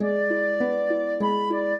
flute-harp
minuet4-9.wav